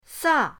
sa4.mp3